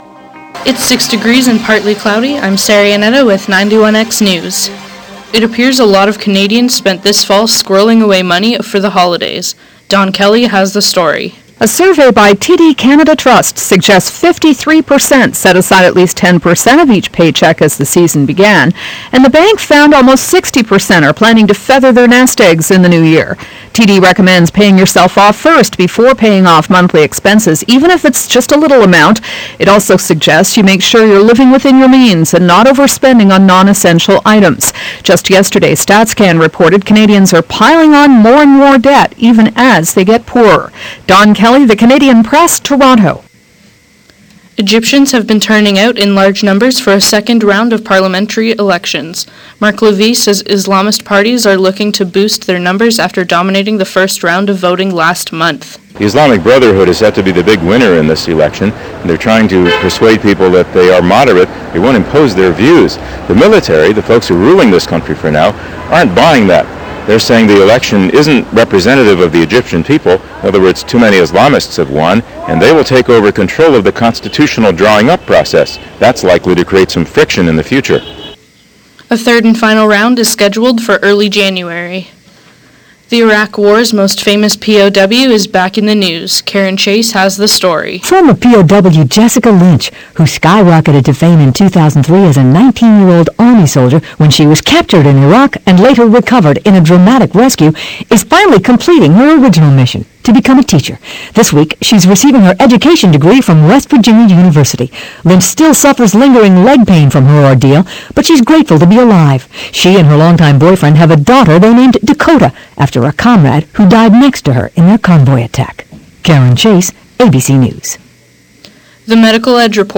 Economists say it appears many Canadians spent this fall saving their money for the Christmas holidays. Egyptians have turned out in large numbers for the parliamentary elections. Iraq prisoners of war are back in the news. For these stories, sports and more listen the 1 p.m. newscast.